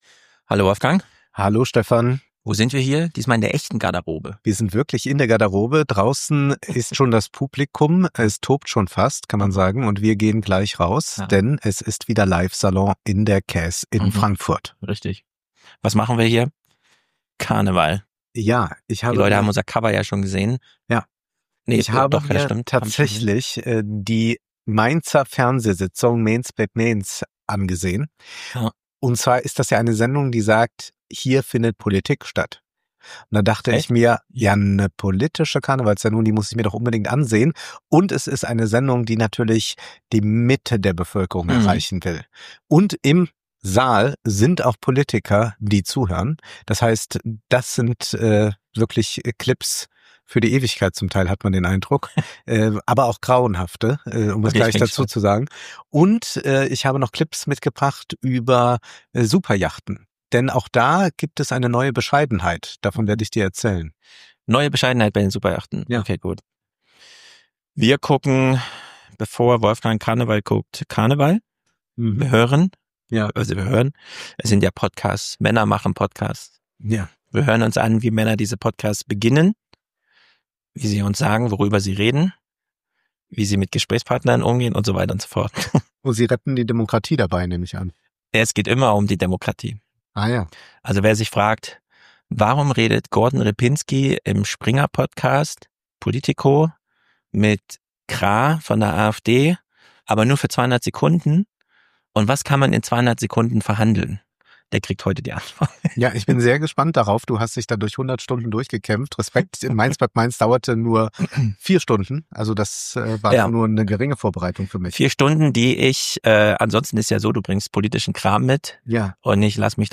Live in Frankfurt: Vorab aus der Garderobe